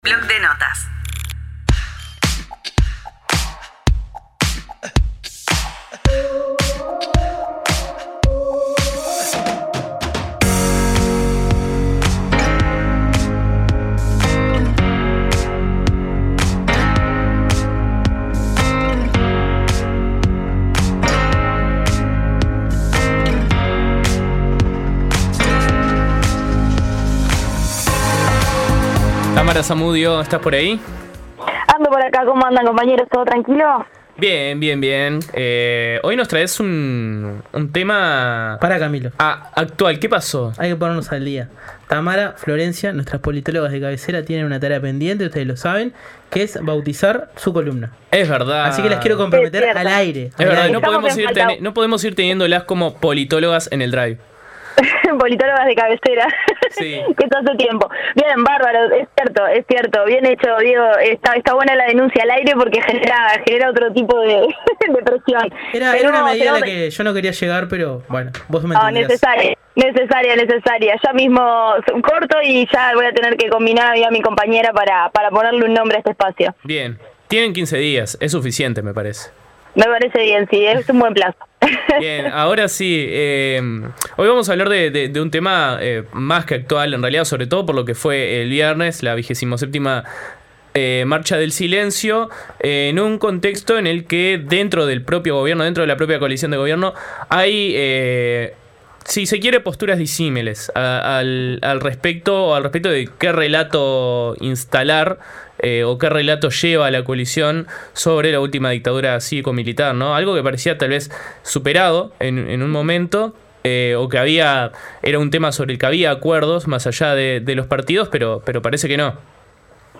Comunidad Udelar, el periodístico de UNI Radio. Noticias, periodismo e investigación siempre desde una perspectiva universitaria.